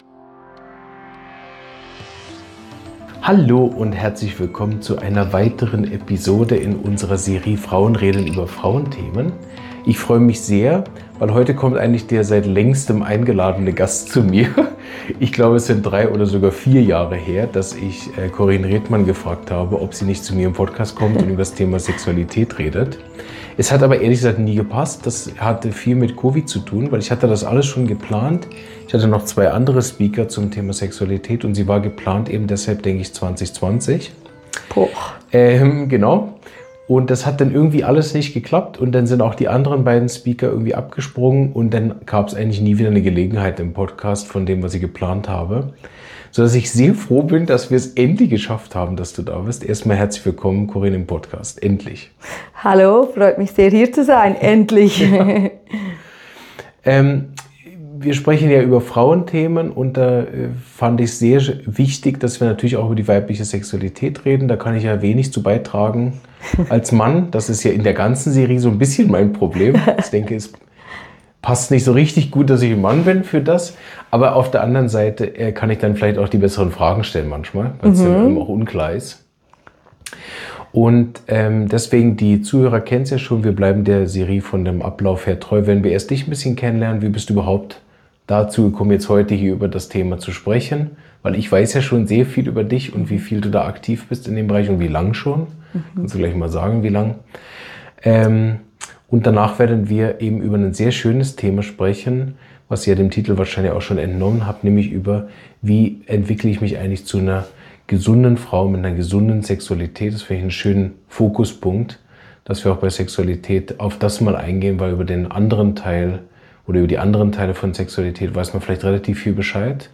Ein ehrliches, tiefgründiges Gespräch für alle, die Sexualität neu denken und leben wollen. https